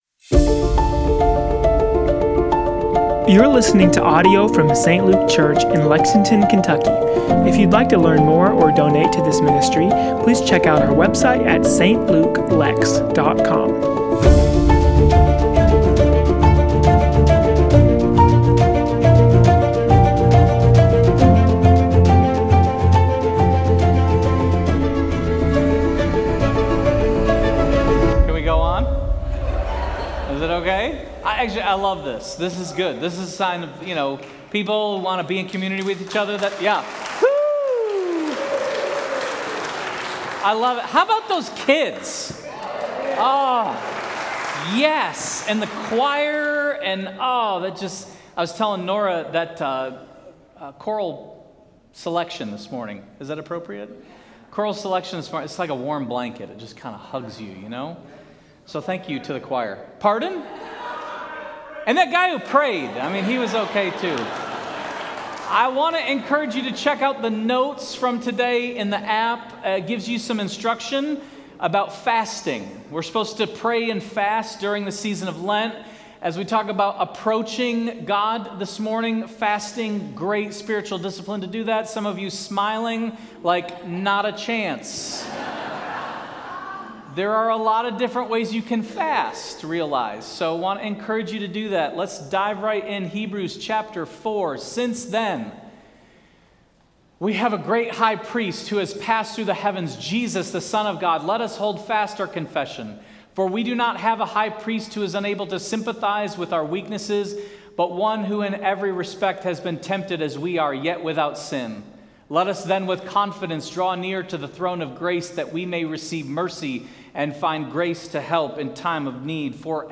Sermon Series: Hebrews: Rediscovering Jesus